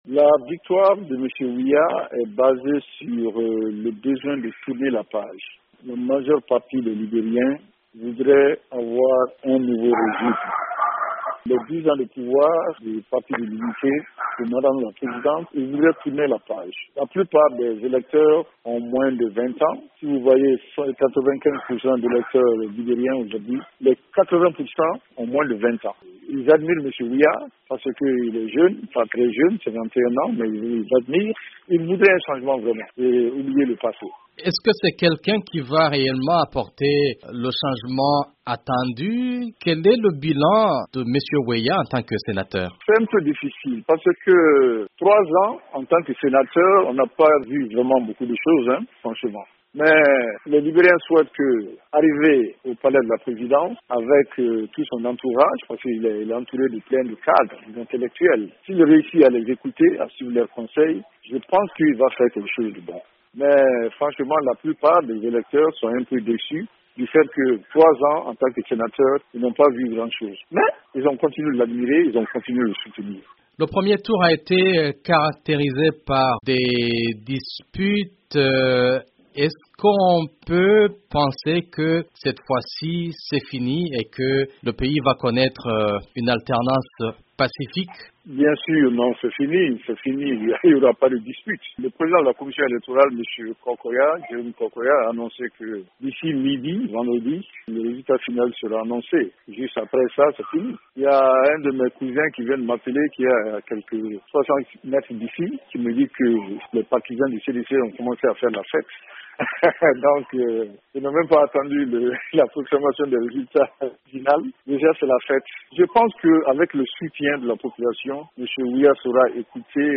Brèves Sonores